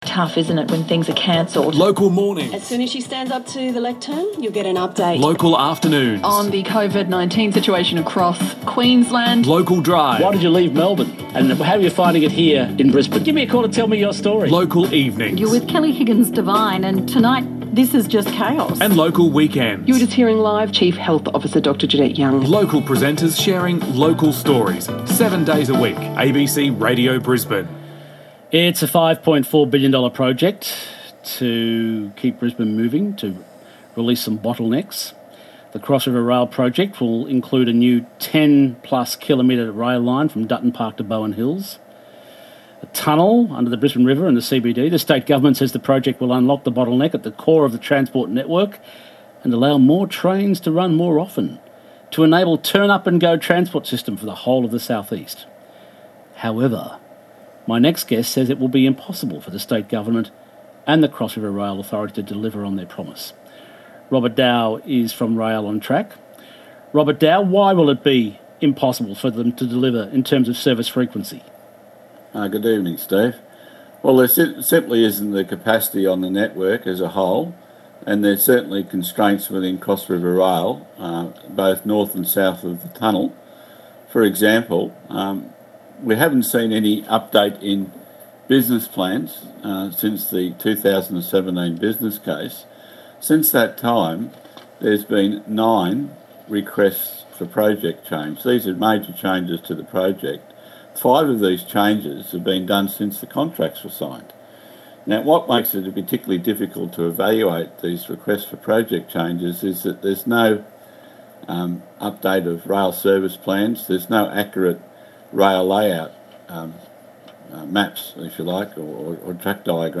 Interviews ABC Brisbane Radio